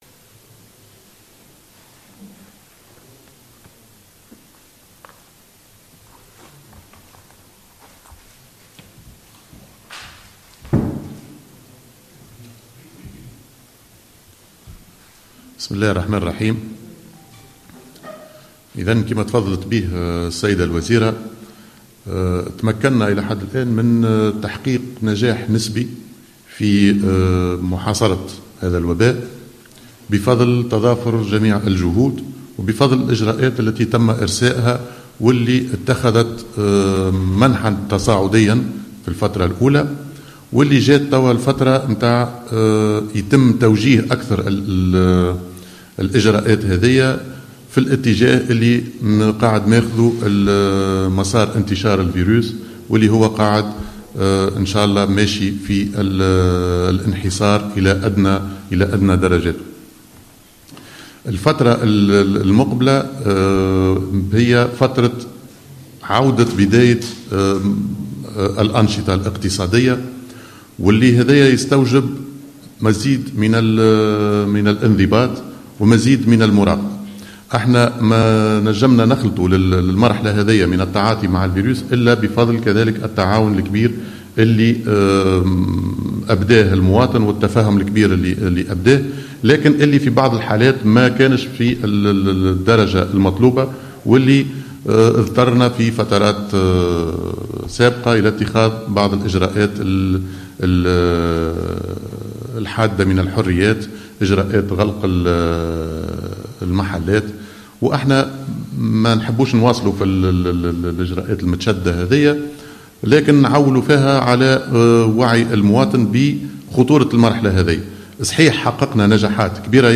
قال وزير الداخلية هشام المشيشي في ندوة صحفية عقدت اليوم الخميس، إن التنقلات بين الولايات بموجب المعايدة ستكون ممنوعة، مؤكدا أنّ الدوريات الأمنية التي سيتم تركيزها بكافة ولايات الجمهورية ستعمل على إرجاع كل من يُحاول التنقل من ولاية إلى أخرى، وتسليط خطايا على المخالفين، باستنثناء بعض الحالات الإنسانية القصوى، أو حالات الترخيص المُحدد للتنقل بين الولايات، حسب تعبيره.